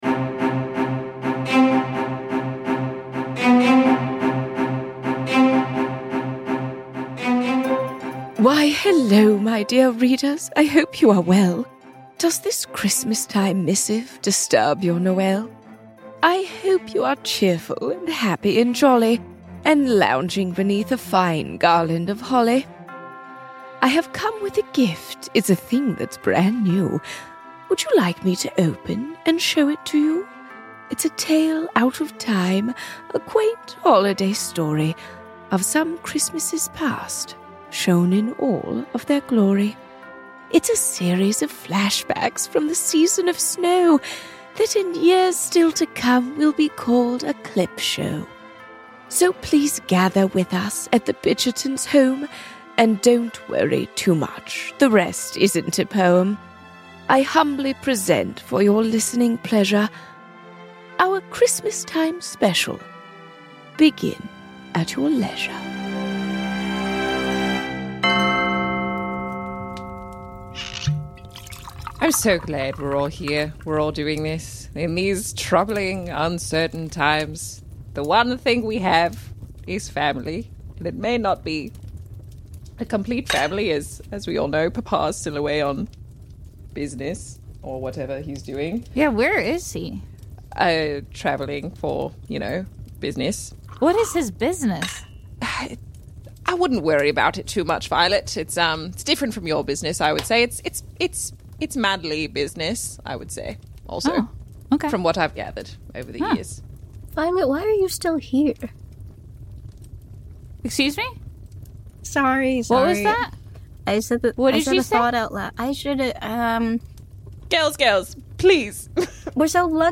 An improvised comedy podcast satirizing the world of Jane Austen that tells the tale of a wealthy family's scandals and shenanigans.
… continue reading 7 bölüm # Comedy # Improv # Audio Drama # Media